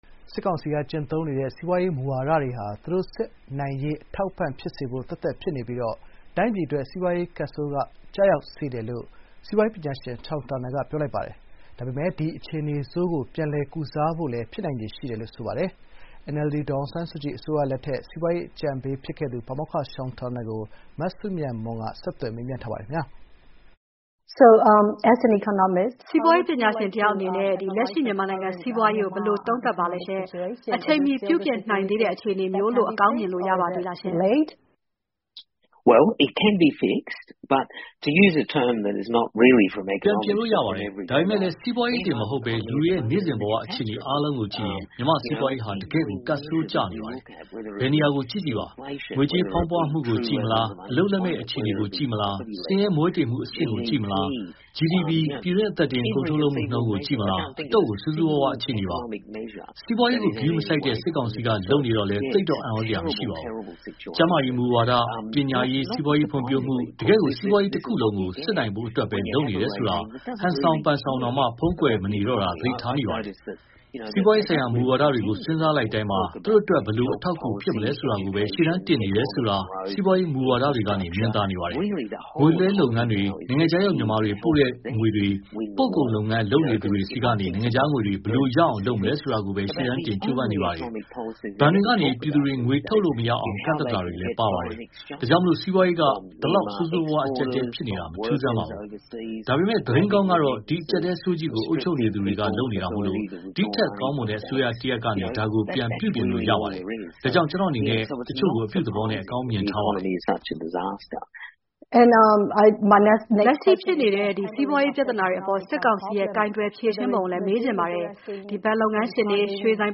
NLD အစိုးရလက်ထက် စီးပွါးရေးအကြံပေး ပါမောက္ခ ရှောင်တာနယ်လ်နဲ့ ဗွီအိုအေ ဆက်သွယ်မေးမြန်းစဥ်။ (သြဂုတ် ၂၈၊ ၂၀၂၄)